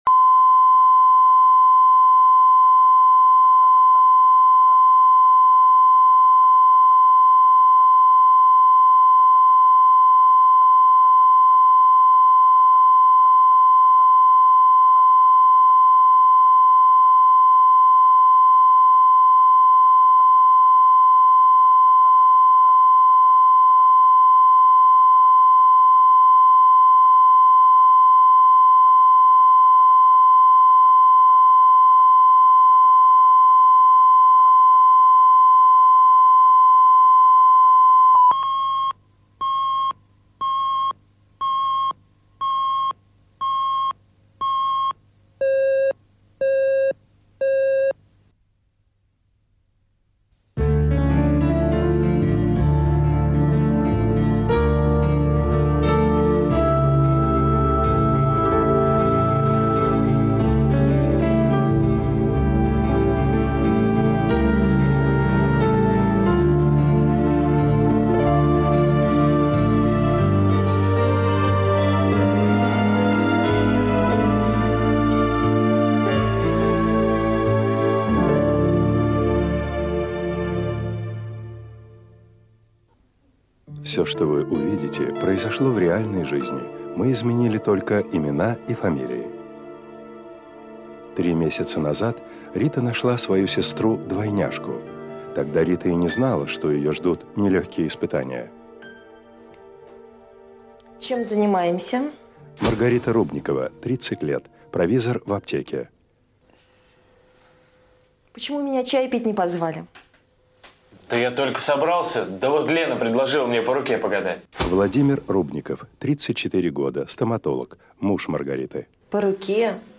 Аудиокнига Сестры | Библиотека аудиокниг